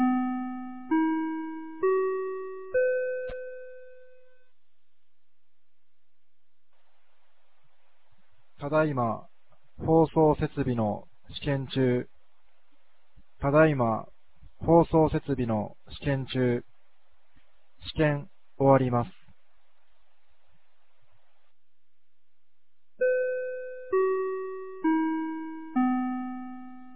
2025年07月12日 16時04分に、由良町から全地区へ放送がありました。
放送音声